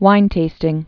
(wīntāstĭng)